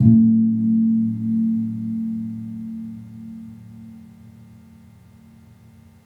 Gong-A1-p.wav